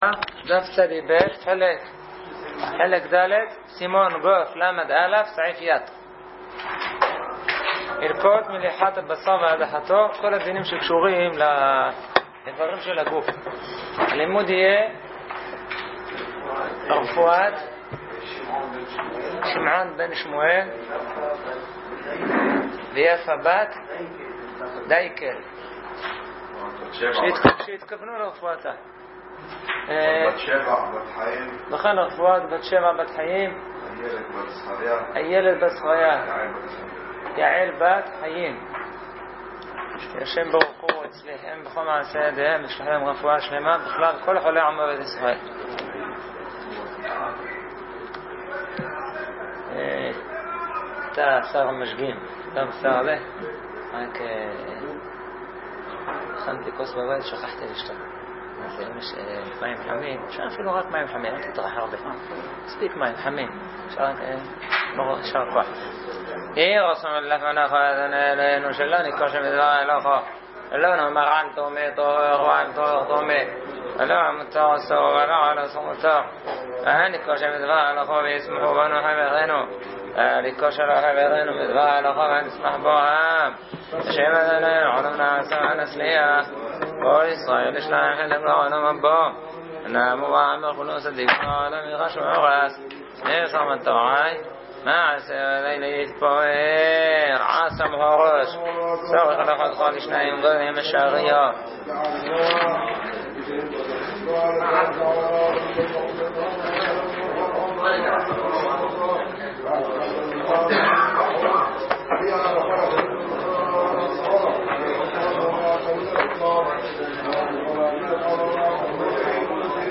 שיעור באלעד - י"ג סיון התשפ"א